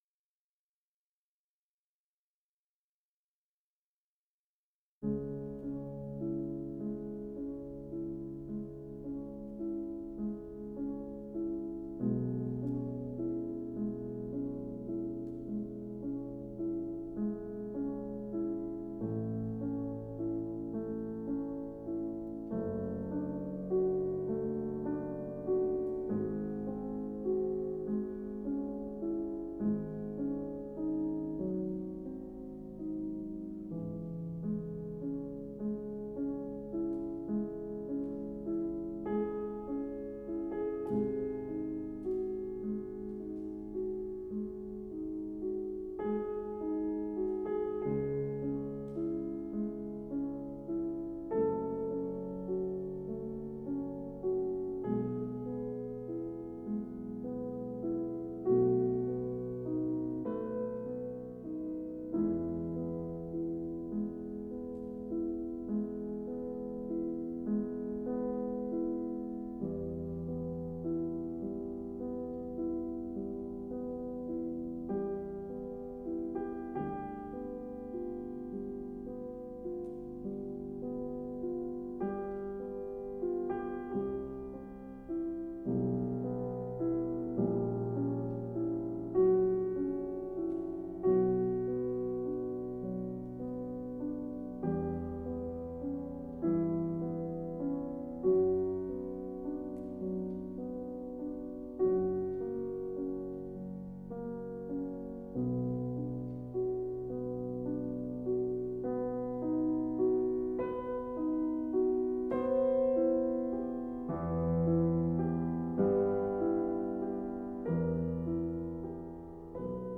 מוזיקה קלאסית